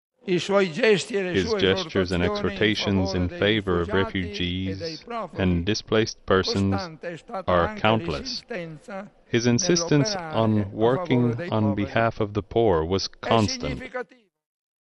An estimated 200,000 people packed into St. Peter’s Square in Rome this morning for Pope Francis’s funeral.
Cardinal Giovanni Battista’s homily praised Francis as a ‘Pope among the people’ – who sided with the marginalised.